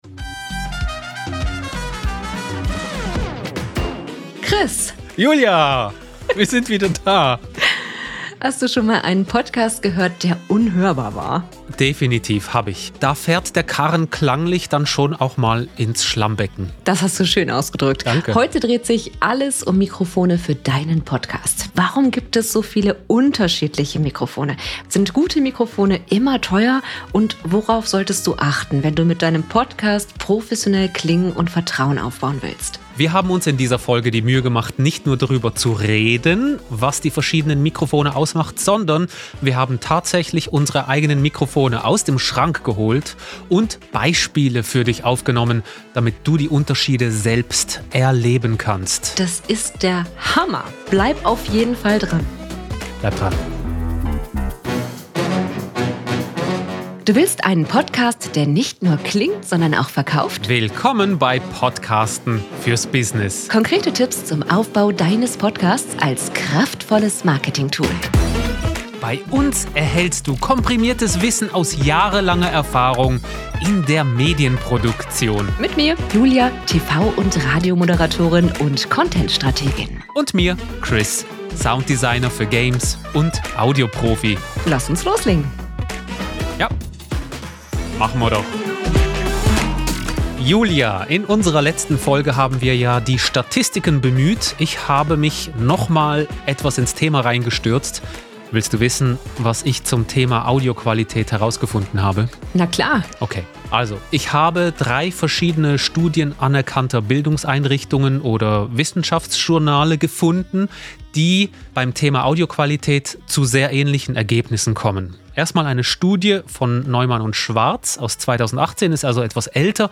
Mit eindrücklichen Mikrofon-Hörbeispielen.